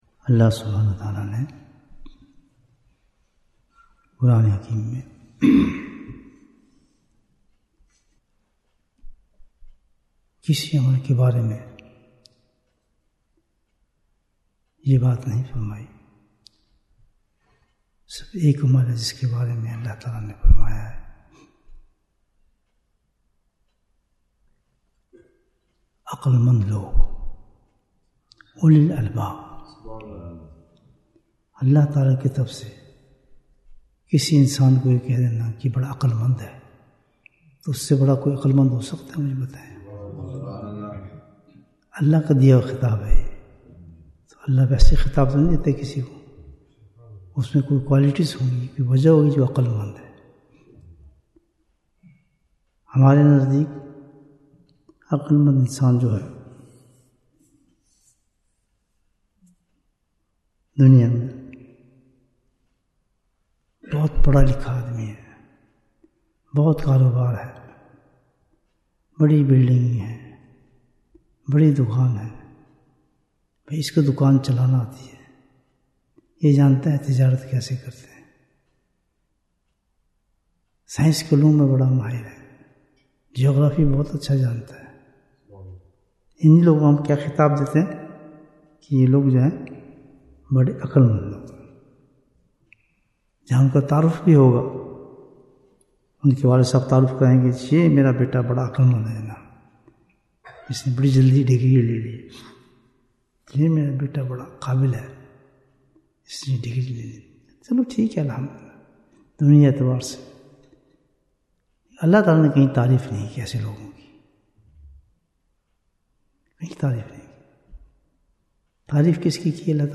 Bayan, 24 minutes14th December, 2024